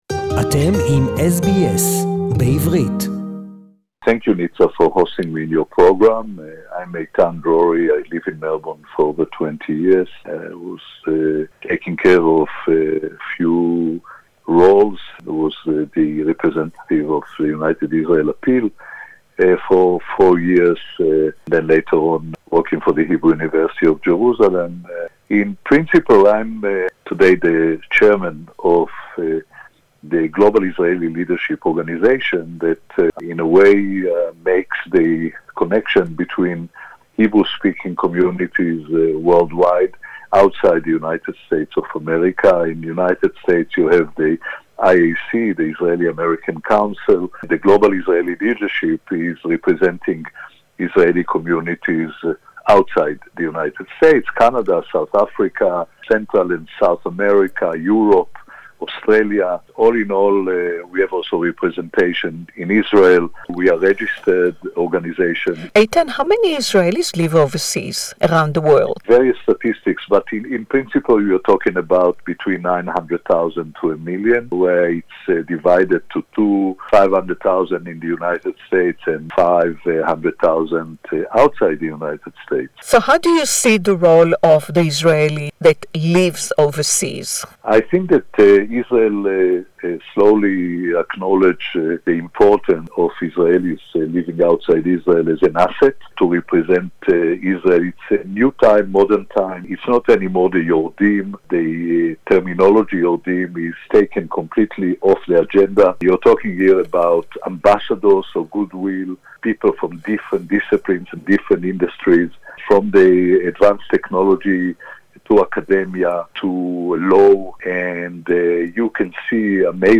(Interview in English)